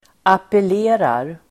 Uttal: [apel'e:rar]